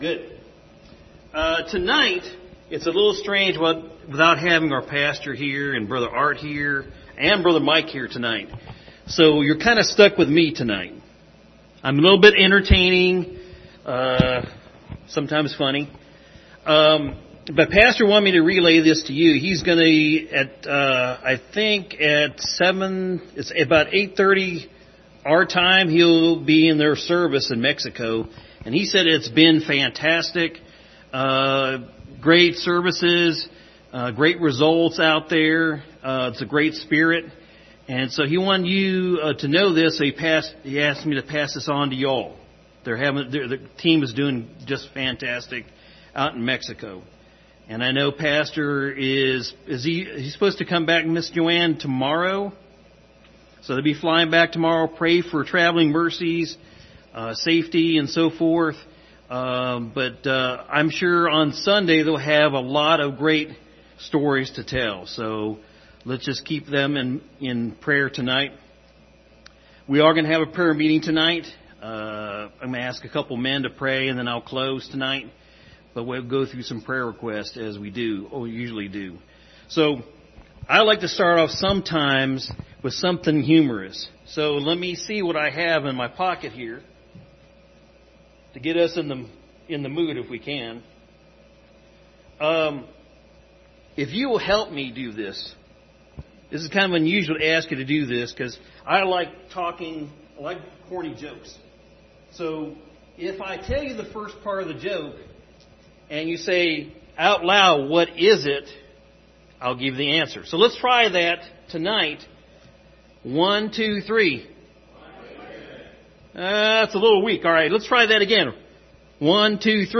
Passage: Phillipians 1:6 Service Type: Wednesday Evening « God is Good